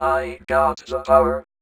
VVE1 Vocoder Phrases
VVE1 Vocoder Phrases 30.wav